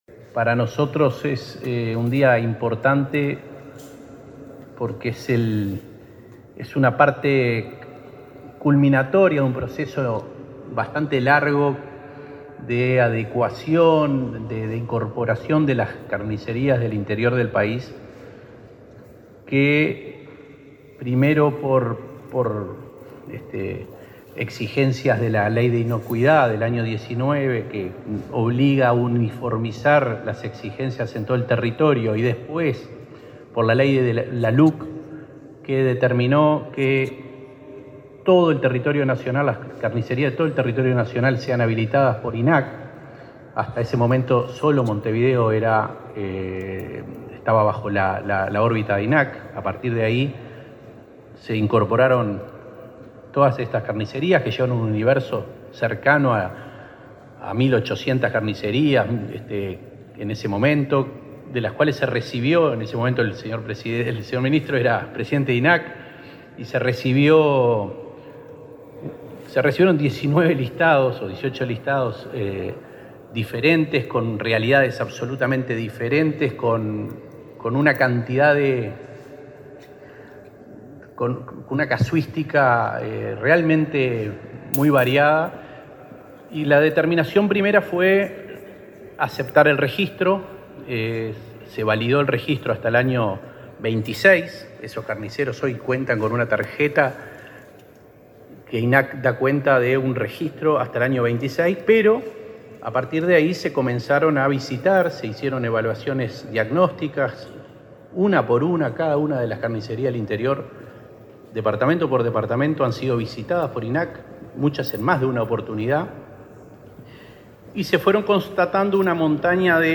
Palabras de autoridades en firma de convenio entre INAC y BROU
Palabras de autoridades en firma de convenio entre INAC y BROU 02/08/2023 Compartir Facebook X Copiar enlace WhatsApp LinkedIn El Instituto Nacional de Carnes (INAC) y el Banco de la República Oriental del Uruguay (BROU) firmaron un convenio este martes 2, para facilitar líneas de crédito a carnicerías. El presidente del INAC, Conrado Ferber, su par del BROU, Salvador Ferrer, y el ministro de Ganadería, Fernando Mattos, destacaron la importancia del acuerdo.